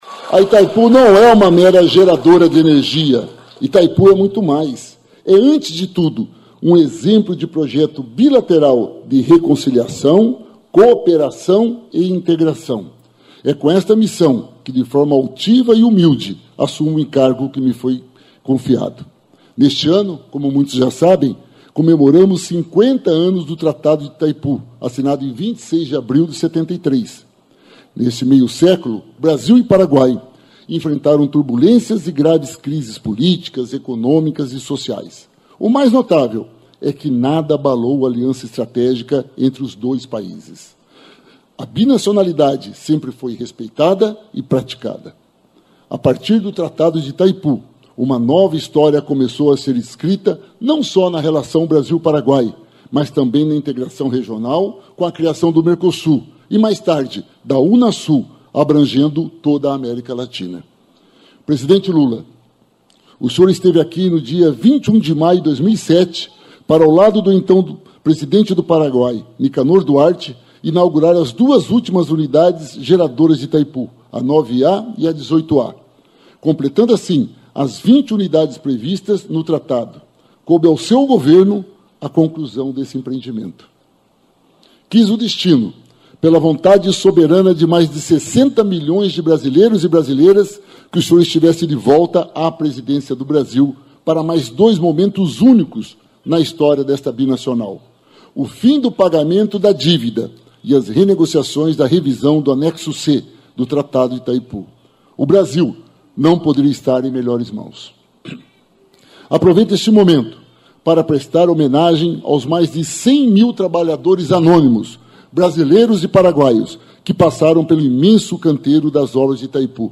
A CBN Cascavel transmitiu a posse de Enio Verri, que segue como diretor-geral de Itaipu até maio de 2027.